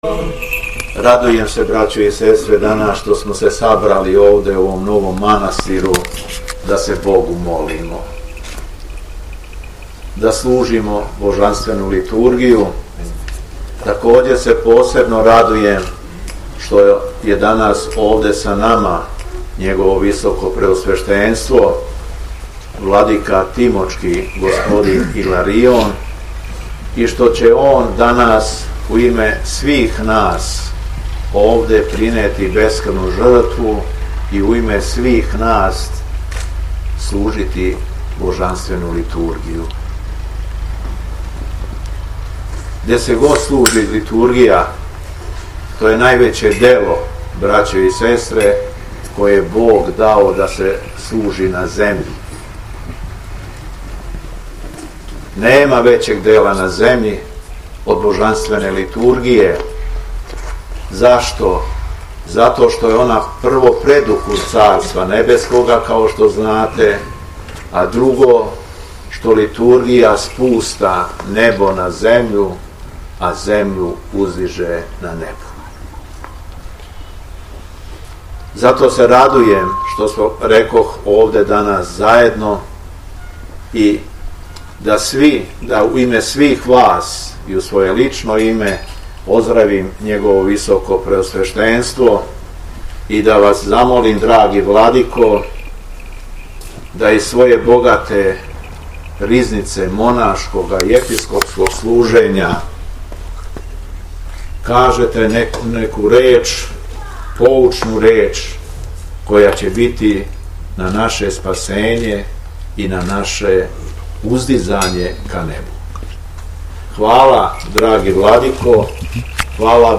СВЕТА АРХИЈЕРЕЈСКА ЛИТУРГИЈА У ЈАГЊИЛУ - Епархија Шумадијска
Беседа Његовог Високопреосвештенства Митрополита шумадијског г. Јована